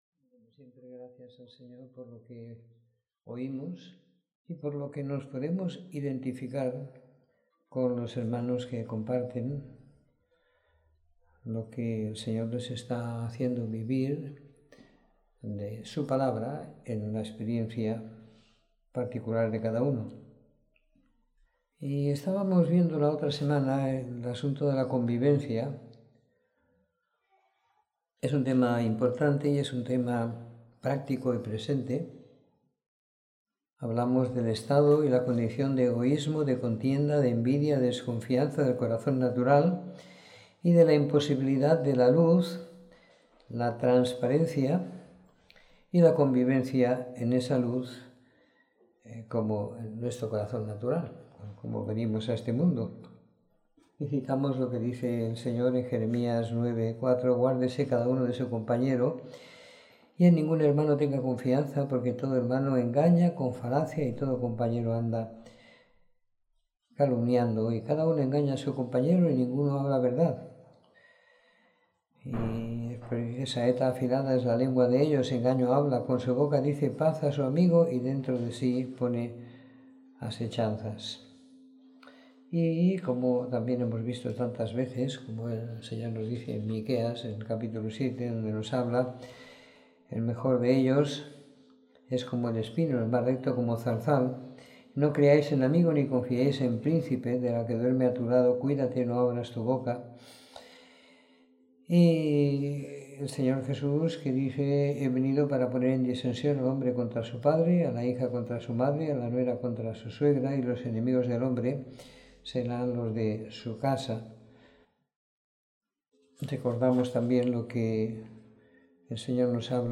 Reunión de Domingo por la Tarde